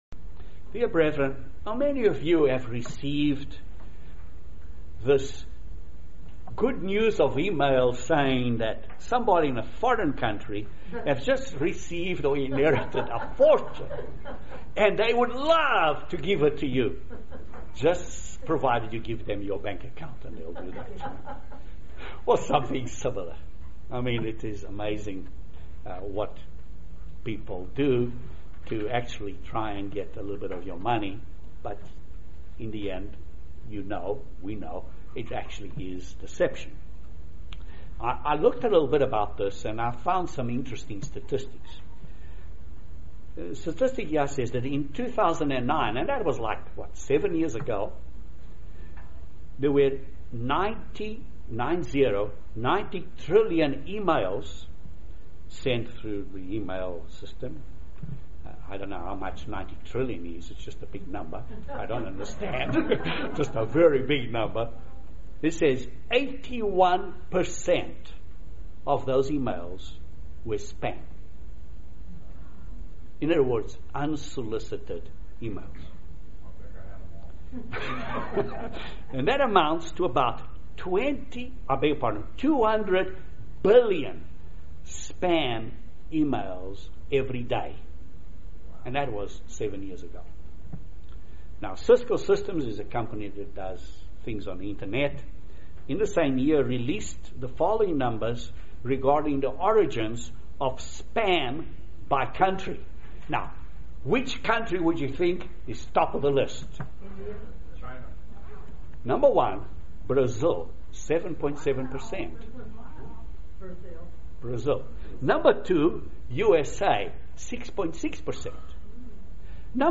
Excellent Message on Deception and the Level of Deception in the world. Is everyone in this world deceived?